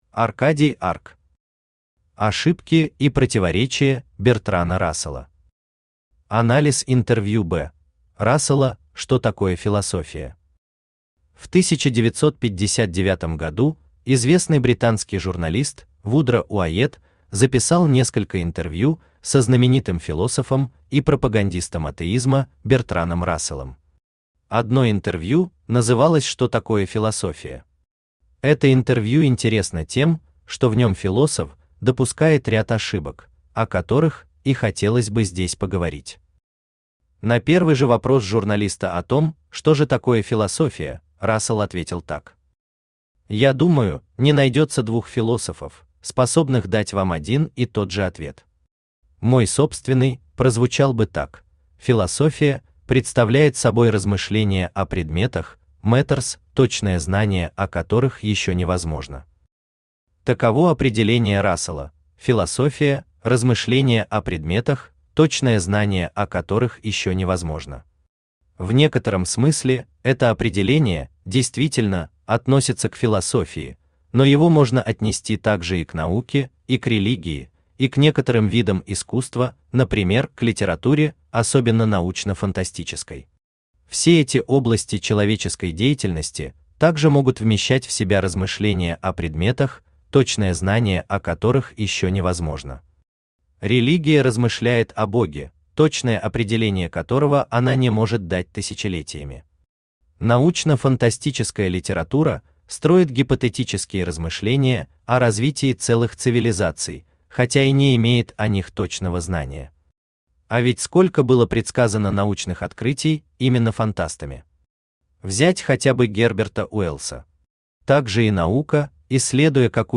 Аудиокнига Ошибки и противоречия Бертрана Рассела | Библиотека аудиокниг
Aудиокнига Ошибки и противоречия Бертрана Рассела Автор Аркадий Арк Читает аудиокнигу Авточтец ЛитРес.